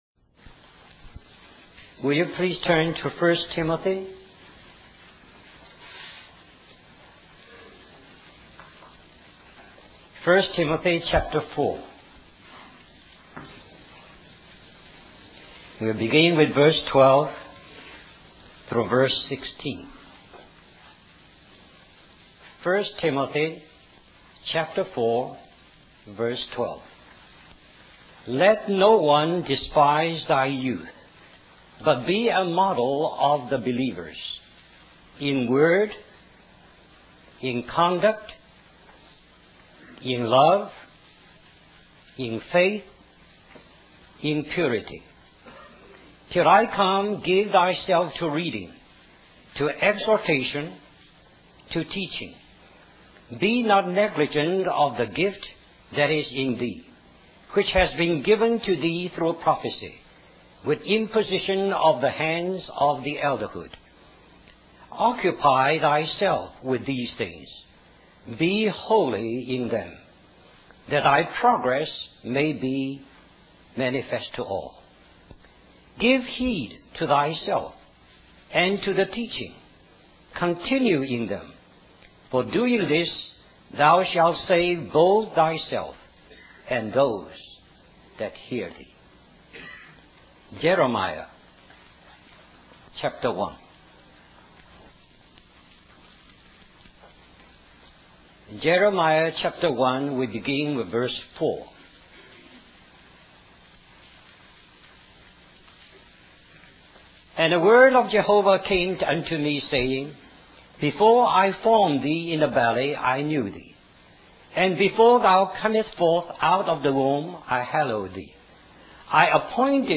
1990 Florida Leadership Conference Stream or download mp3 Summary The remainder of this message may be found at this link .